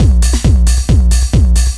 DS 135-BPM D5.wav